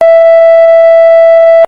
Beeping Sound Button - Free Download & Play
Sound Effects Soundboard641 views